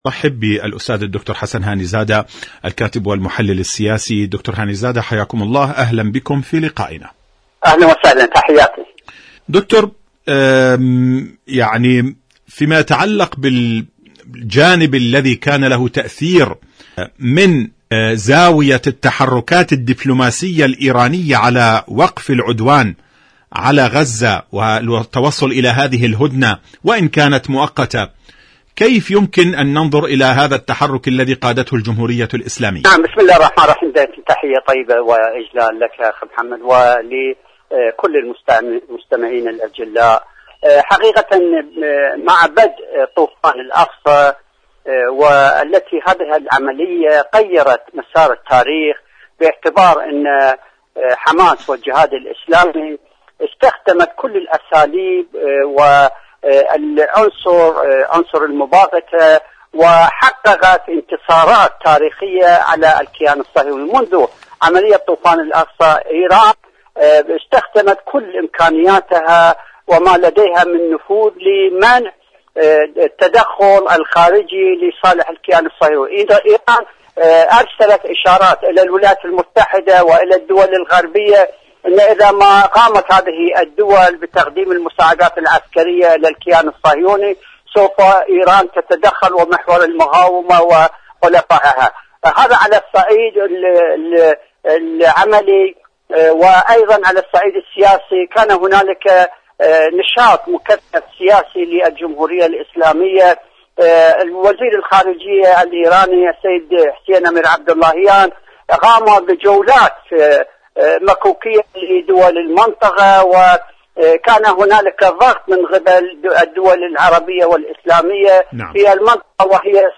مقابلات إذاعية برنامج ايران اليوم المشهد السياسي